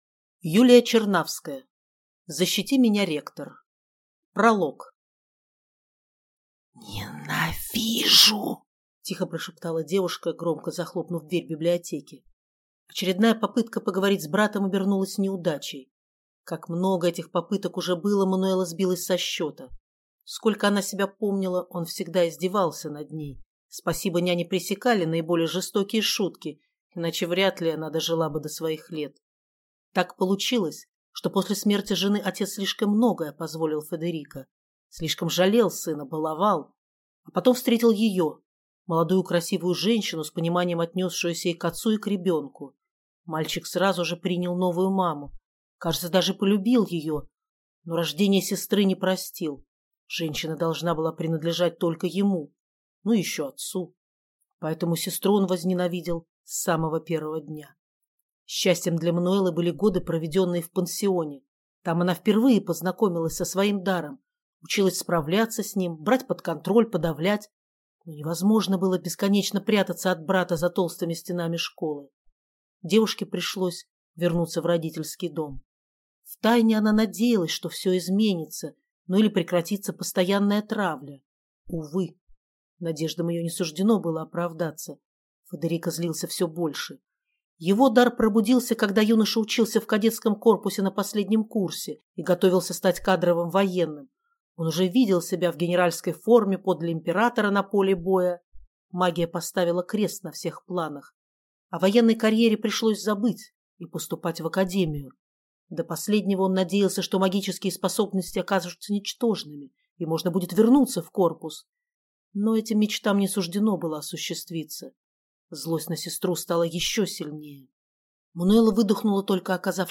Аудиокнига Защити меня, ректор | Библиотека аудиокниг